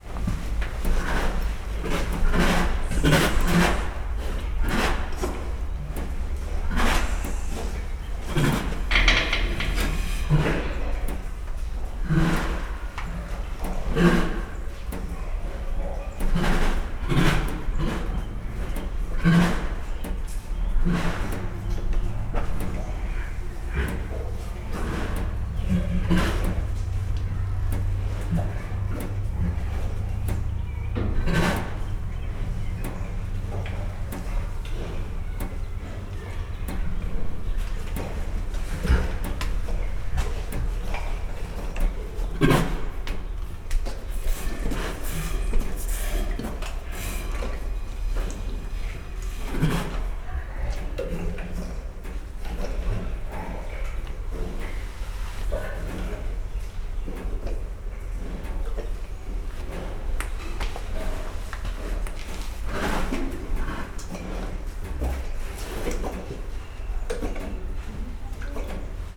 egymassalzarterbenkommunikalnak01.09.WAV